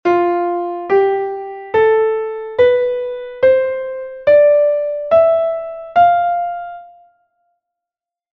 Modos eclesiásticos ou gregorianos
autentico finalis fa, repercusio do